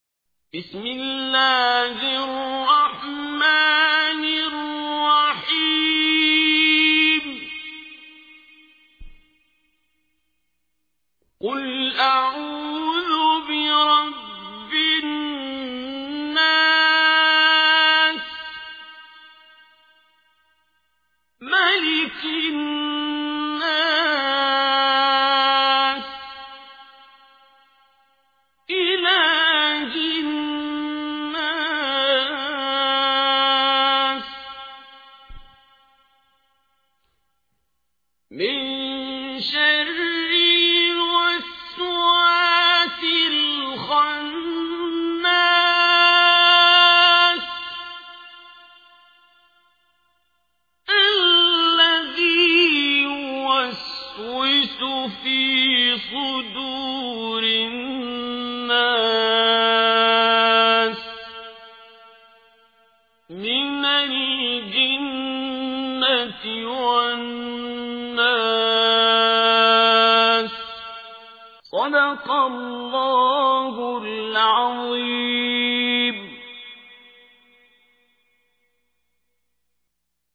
تحميل : 114. سورة الناس / القارئ عبد الباسط عبد الصمد / القرآن الكريم / موقع يا حسين